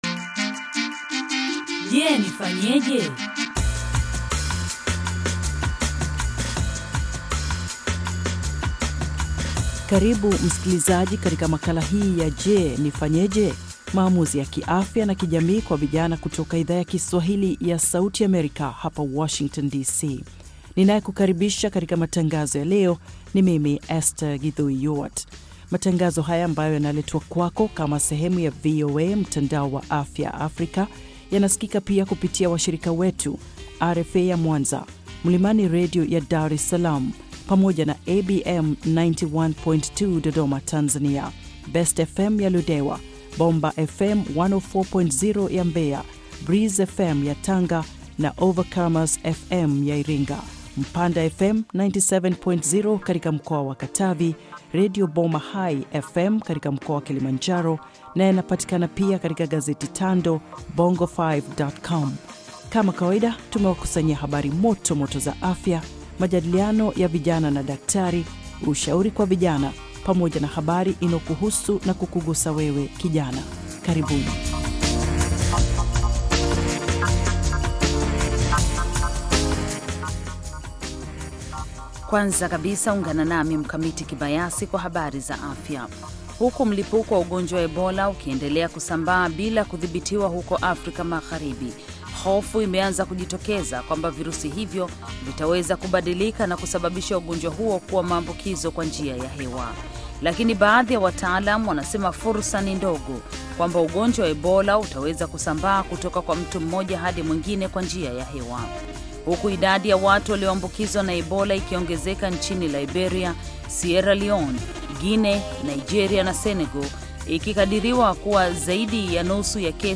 Maamuzi ya Afya na Kijamii kwa Vijana - Kipindi cha dakika 30 kinacholenga vijana kwa kufuatulia maisha ya vijana, hasa wasichana, kuwasaidia kufanya maamuzi mazuri ya kiafya na kijamii ambayo yanaweza kuwa na maana katika maisha yao milele. Kipindi hiki kina sehemu ya habari za afya, majadiliano, na maswali na majibu kwa madaktari na wataalam wengine.